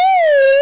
Slidedown
SlideDown.wav